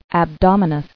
[ab·dom·i·nous]